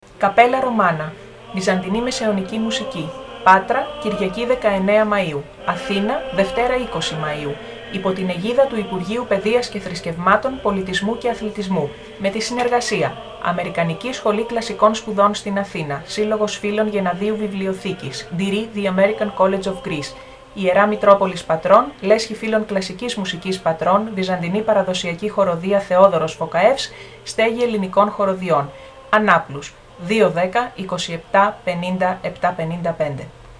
ΡΑΔΙΟΦΩΝΙΚΟ ΜΗΝΥΜΑ
CAPPELLA_ROMANA_RADIOspot.mp3